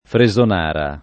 Fresonara [ fre @ on # ra ]